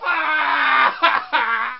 PERSON-Yell+Male+Wahhh